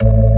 orgTTE54005organ-A.wav